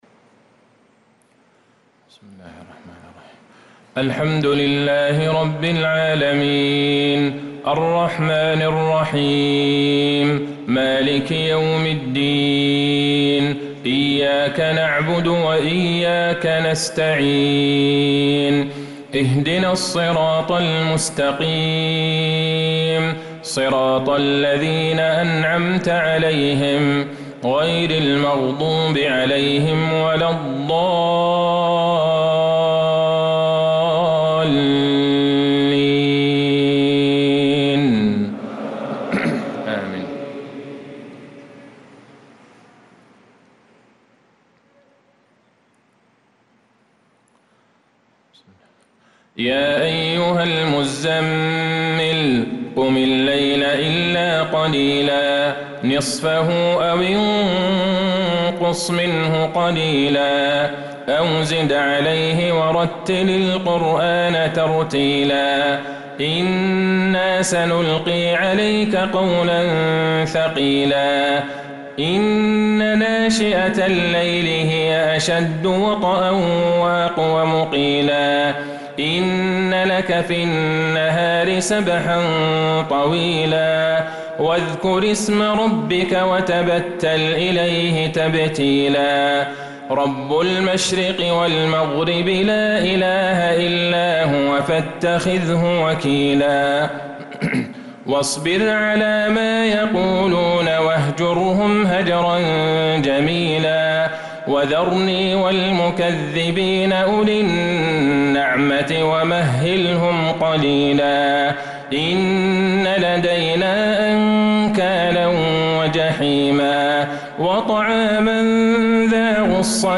صلاة العشاء للقارئ عبدالله البعيجان 16 محرم 1446 هـ